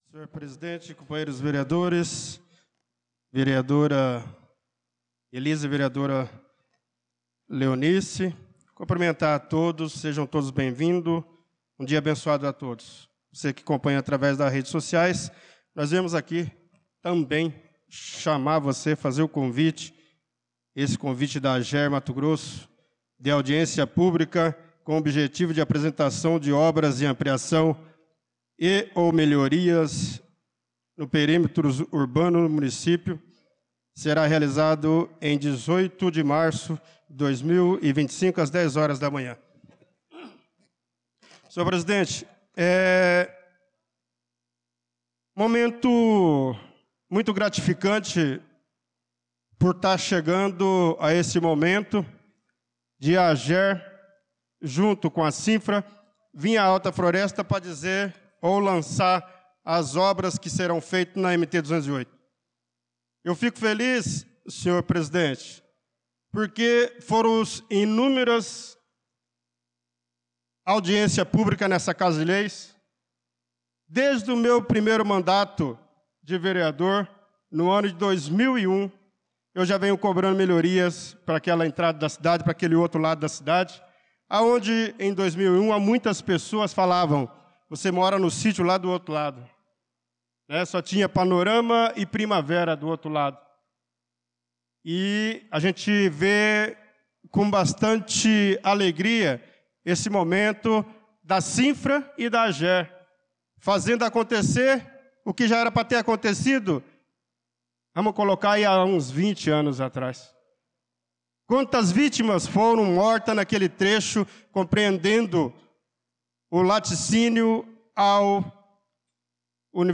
Pronunciamento do vereador Bernardo Patrício na Sessão Ordinária do dia 18/03/2025